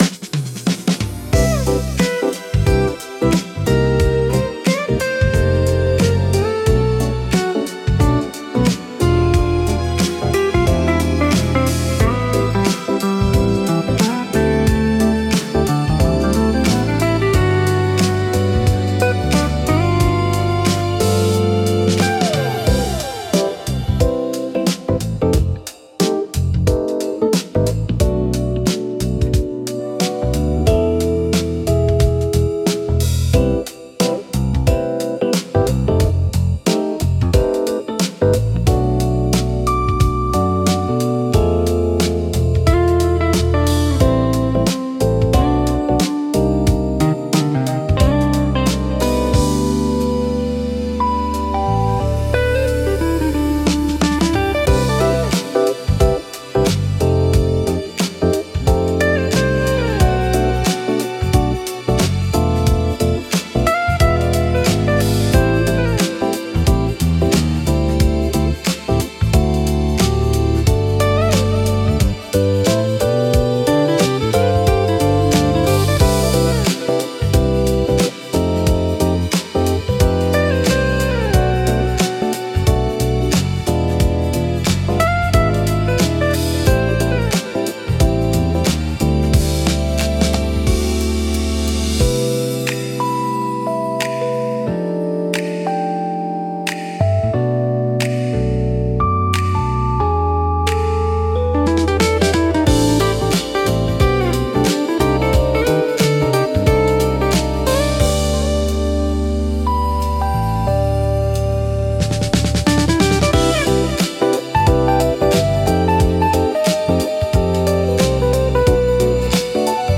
リラックス効果が高く、会話の邪魔をせず心地よい背景音として居心地の良さを高めます。